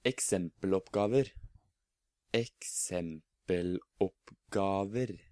Norwegian Stress – Examples of Pronunciation
This is how I pronounce some of the words mentioned in this topic.